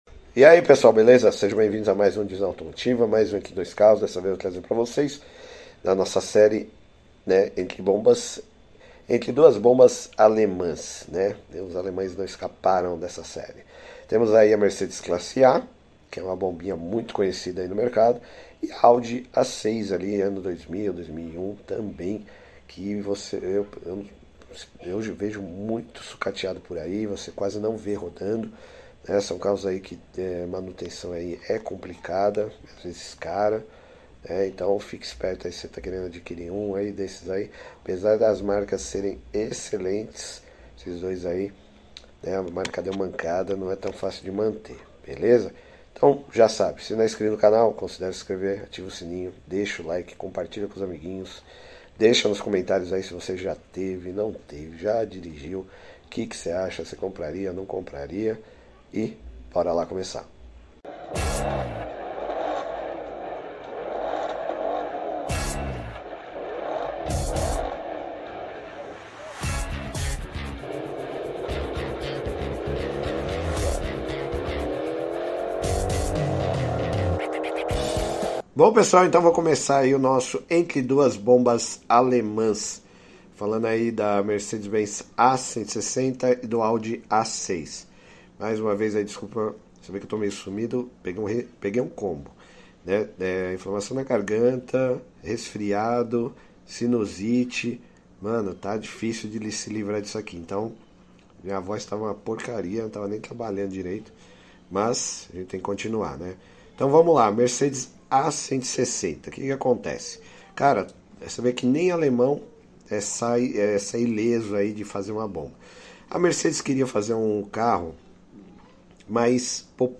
MERCEDES A160 X AUDI A6 sound effects free download